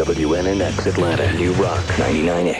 01. station identification (0:02)